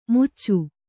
♪ muccu